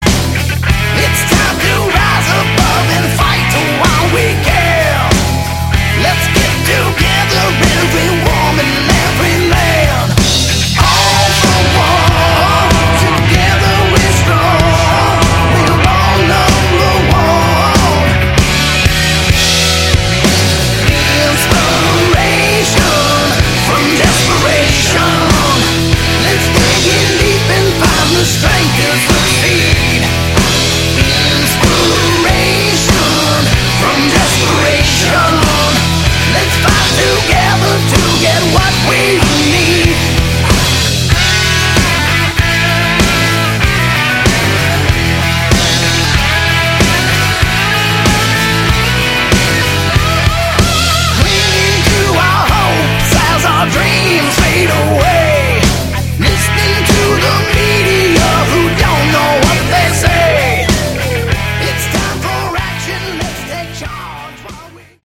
Category: Hard Rock
vocals, harmonica, tambourine, keyboards
guitar, backing vocals
drums
bass